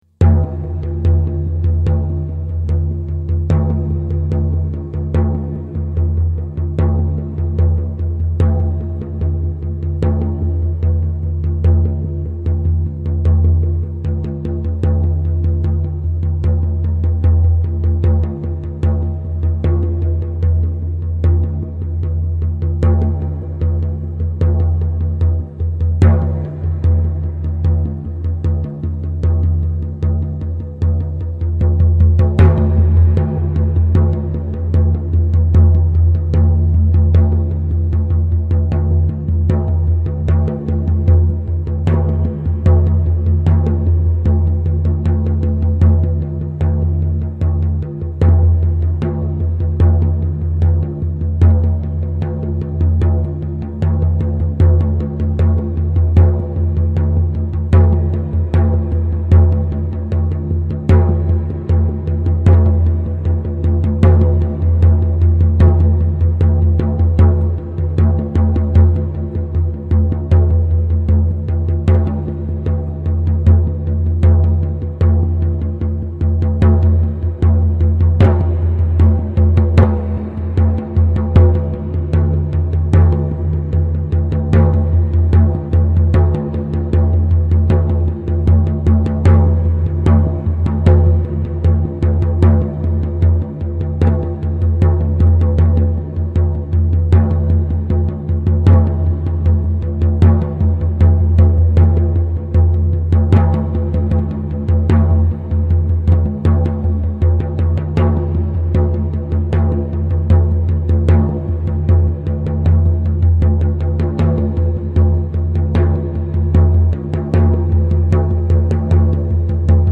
Звук для погружения в транс